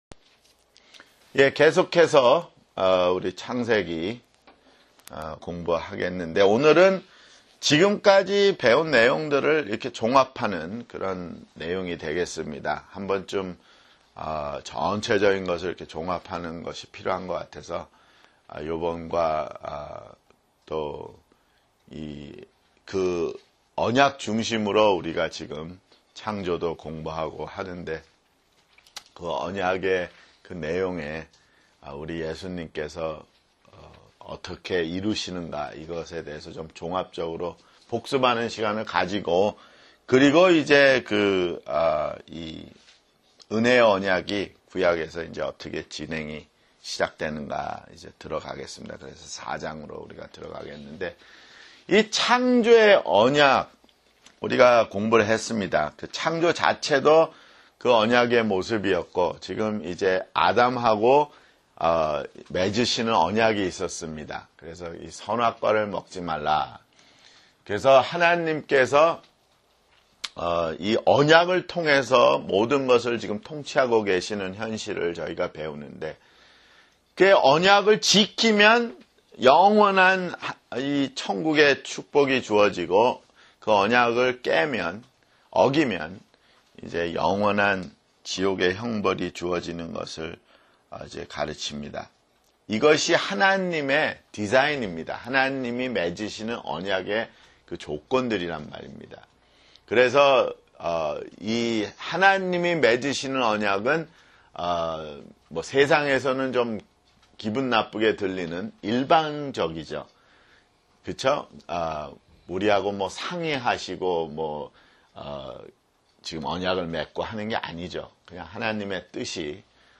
[성경공부] 창세기 (19)